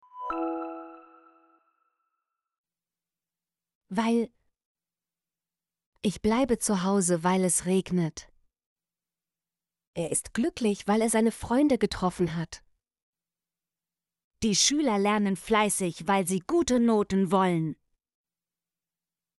weil - Example Sentences & Pronunciation, German Frequency List